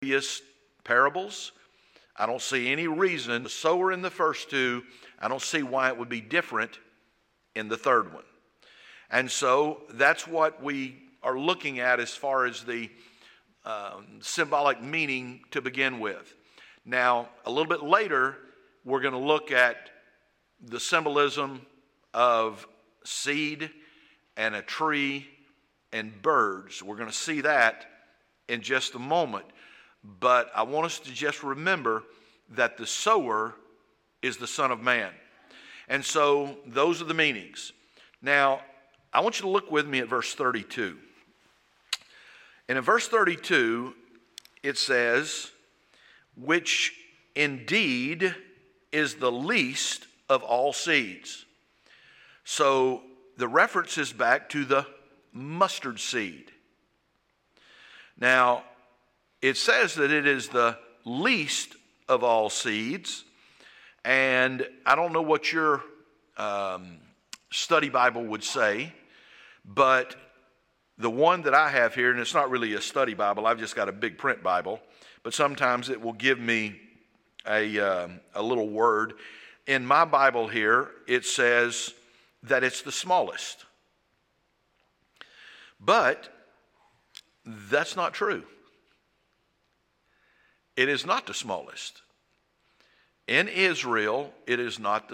There is not a full recording fo this sermon.